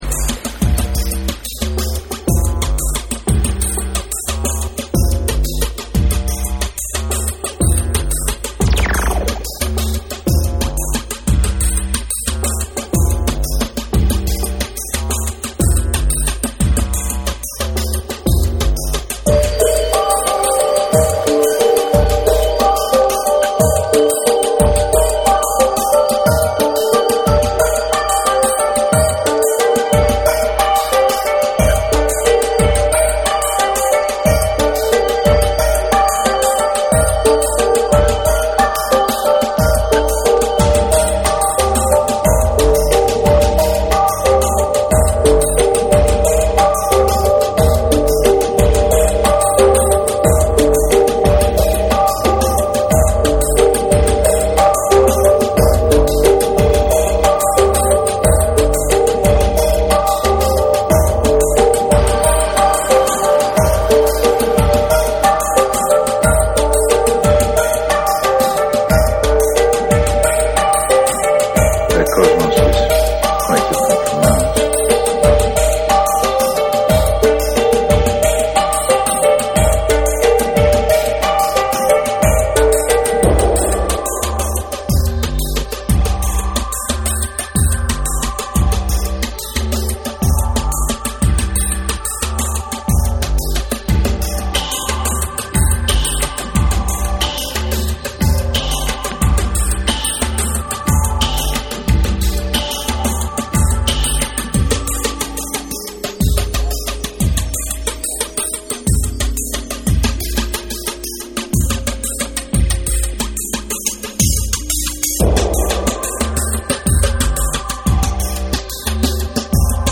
TECHNO & HOUSE / AMBIENT / INTELLIGENCE TECHNO